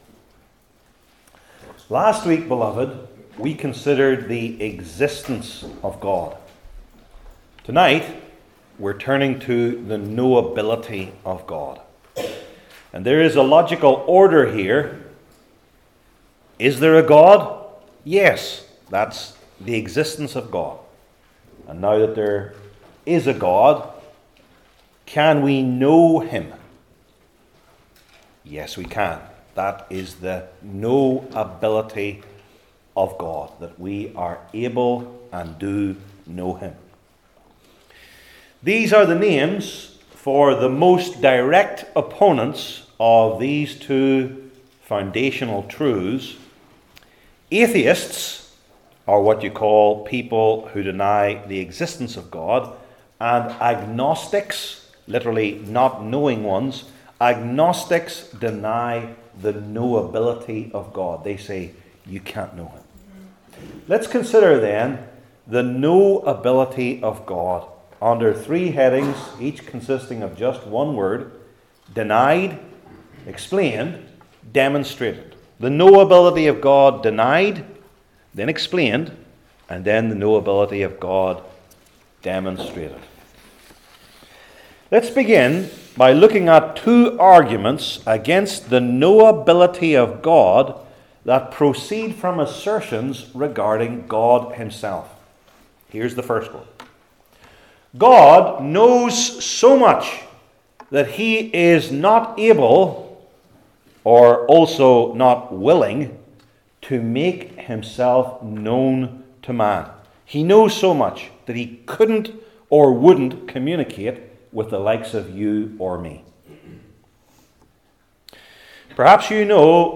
New Testament Individual Sermons I. Denied II.